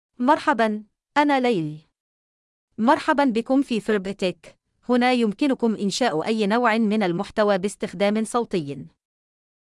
FemaleArabic (Bahrain)
Laila is a female AI voice for Arabic (Bahrain).
Voice sample
Listen to Laila's female Arabic voice.
Female
Laila delivers clear pronunciation with authentic Bahrain Arabic intonation, making your content sound professionally produced.